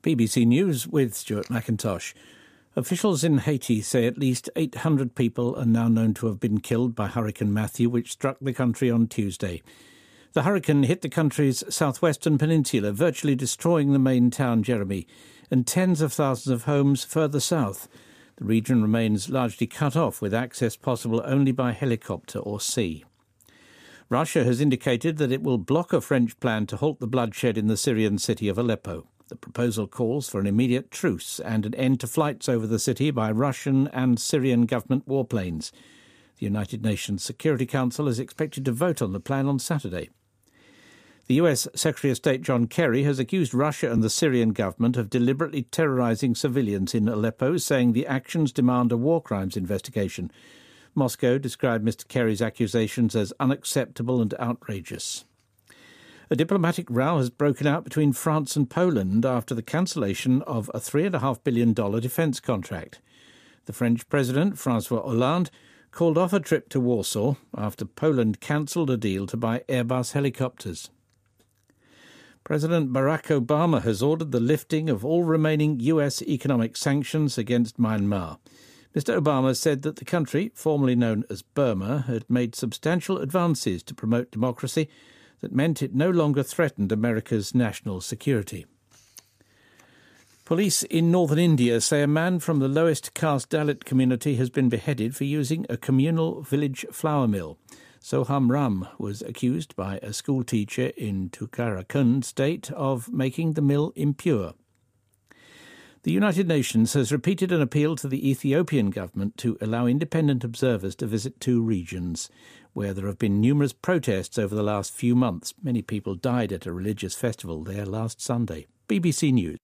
BBC news,奥巴马宣布解除对缅甸经济制裁